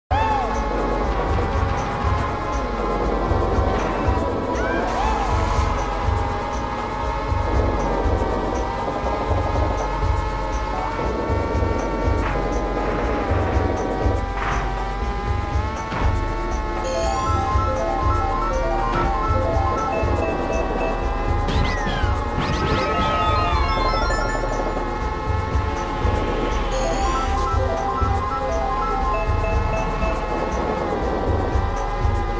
Mario Kart 64 sound effects free download